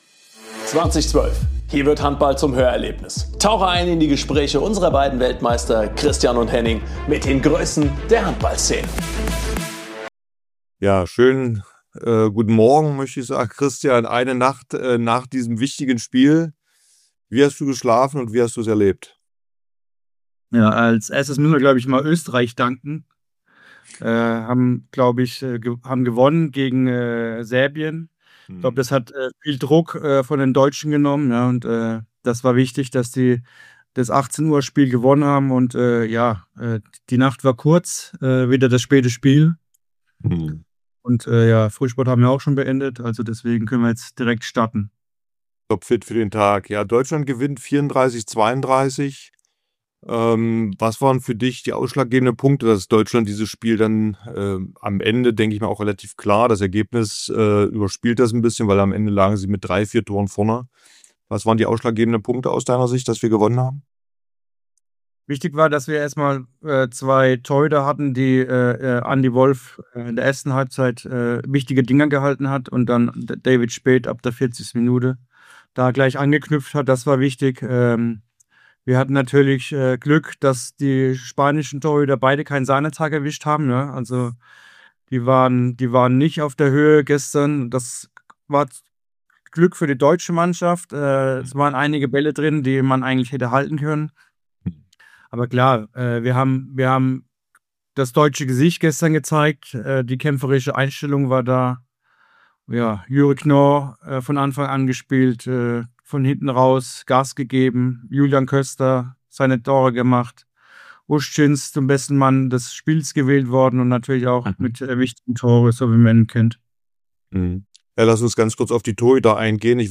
Am Mikrofon: Christian Zeitz & Henning Fritz Zwei Weltmeister.